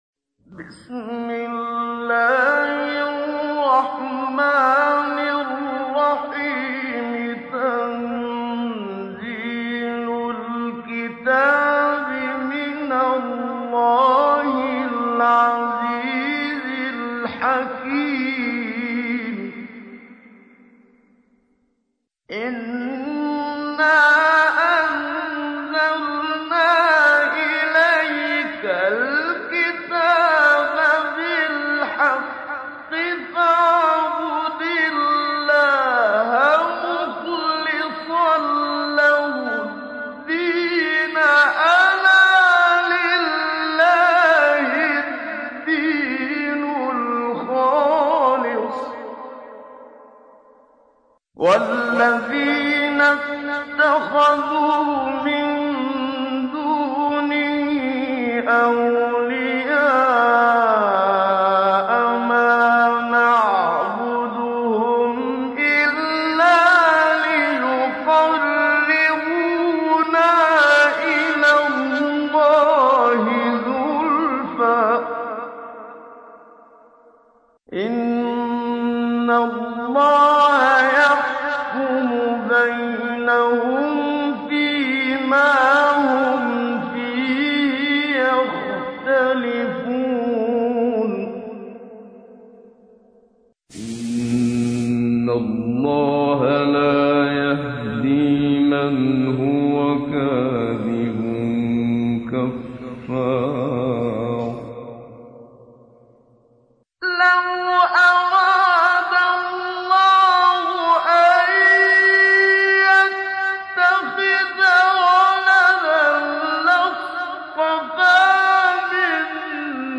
تحميل : 39. سورة الزمر / القارئ محمد صديق المنشاوي / القرآن الكريم / موقع يا حسين